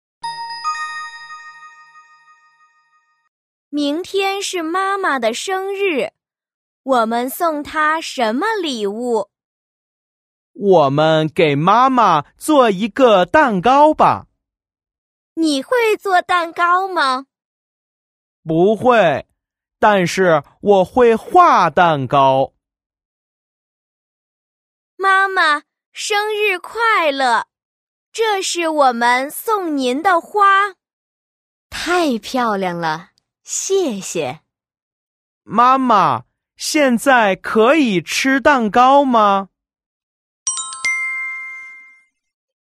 Đoạn hội thoại 1:
Đoạn hội thoại 2: